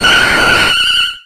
Cries
VILEPLUME.ogg